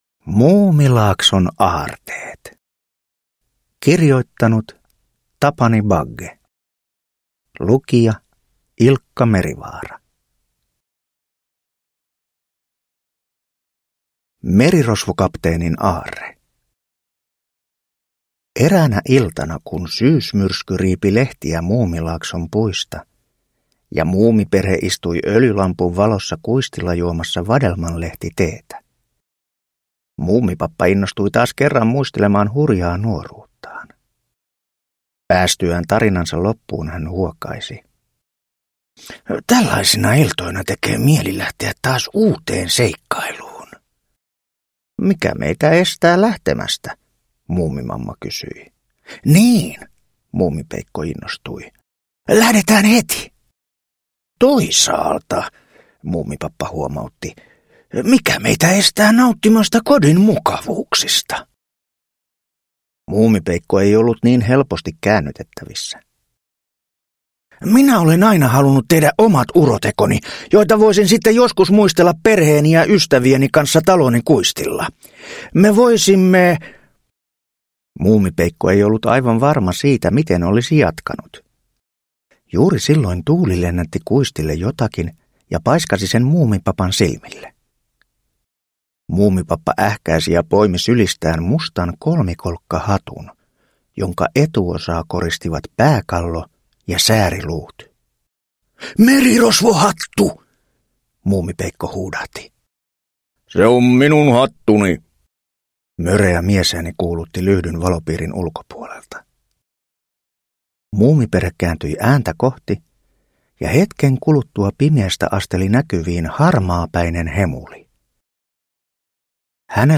Muumilaakson aarteet – Ljudbok